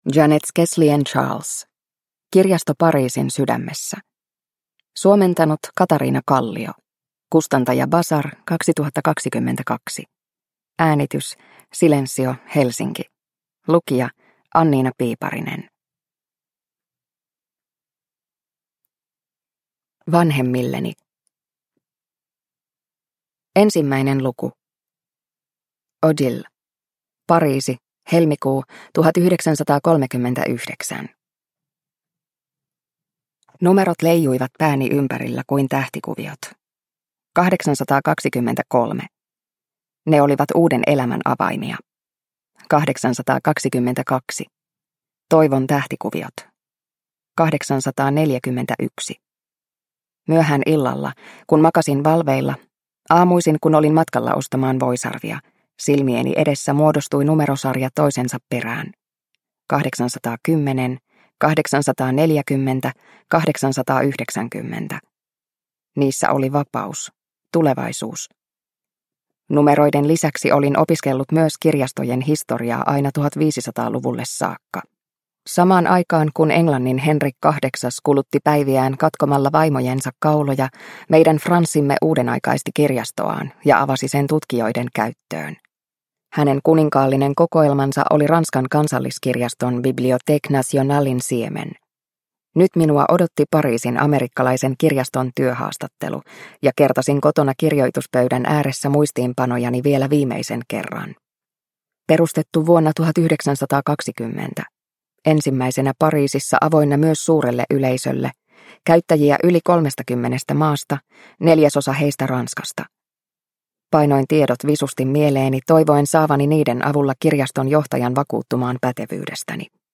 Kirjasto Pariisin sydämessä – Ljudbok – Laddas ner